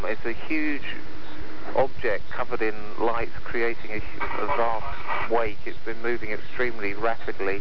Transcripts of telephone call.
From a Greenpeace Protestor: